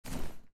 action_get_up.mp3